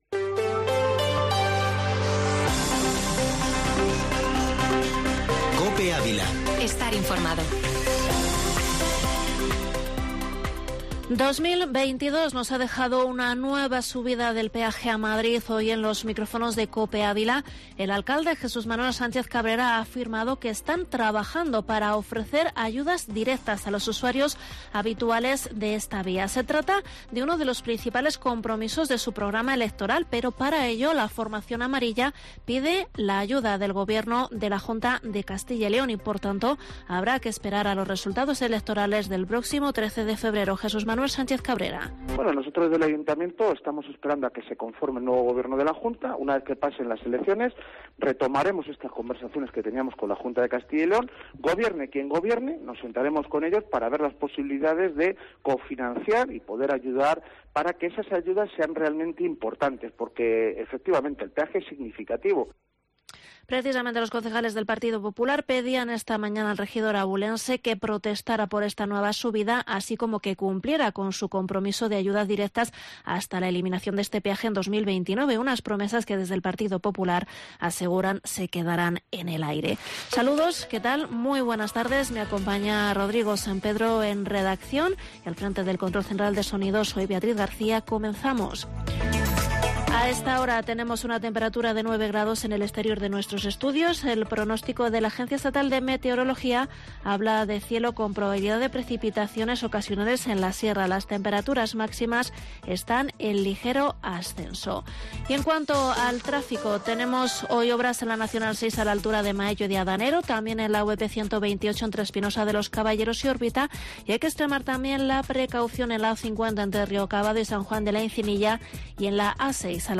Informativo Mediodía Cope en Avila 10/1/2022